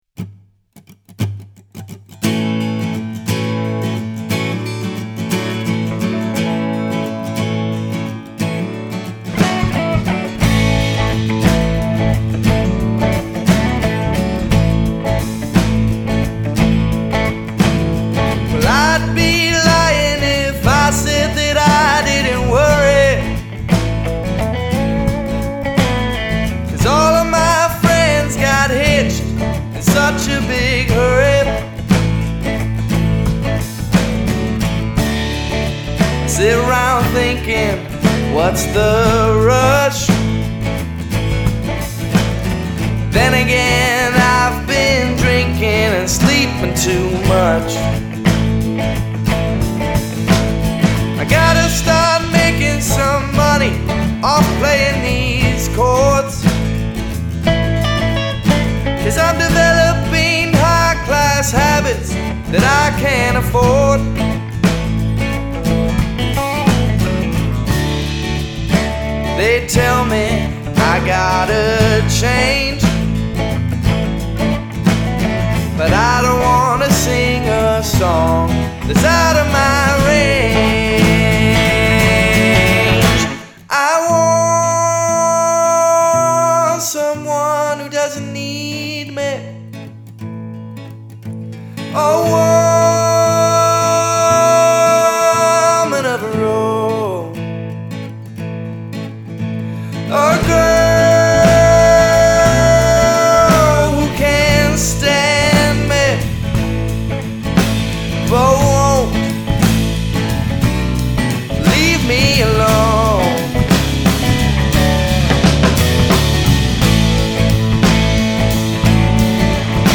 right in our living room
vocals and acoustic guitar
electric guitar and backing vocals
bass
drums and backing vocals